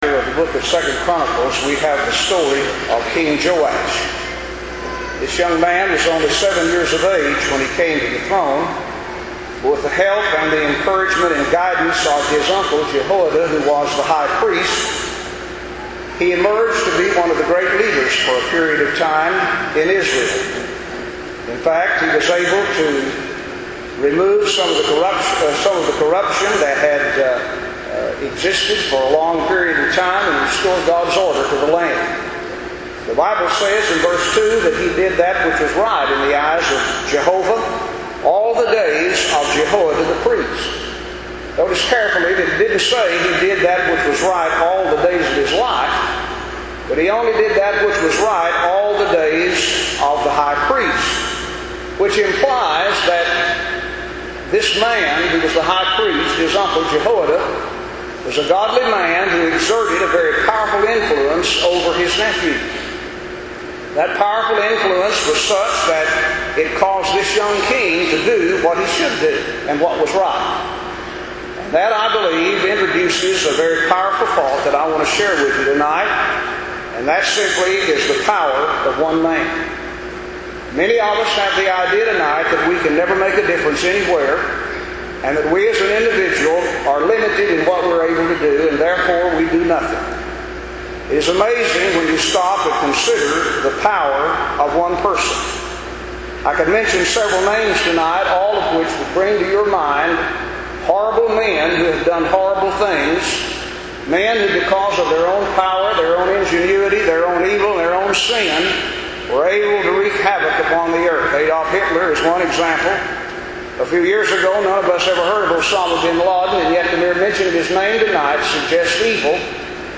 The Power of One Man MP3 Link In this sermon from 2009